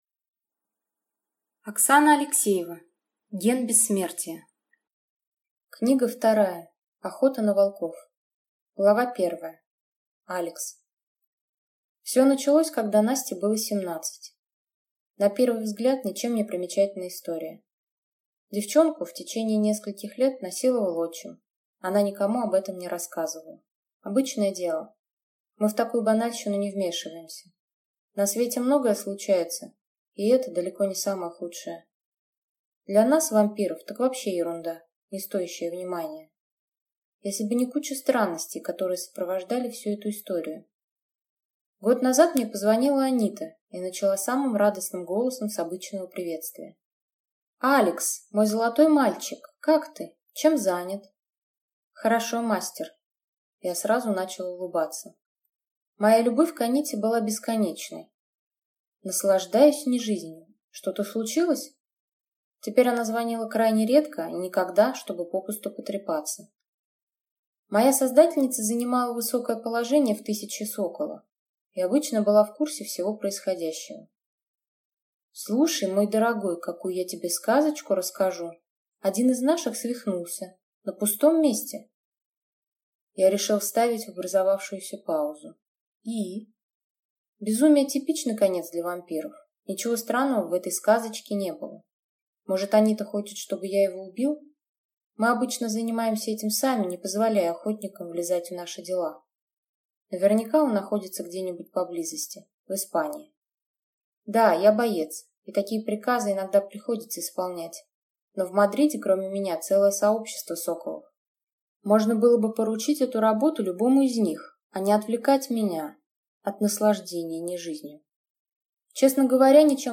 Аудиокнига Охота на Волков | Библиотека аудиокниг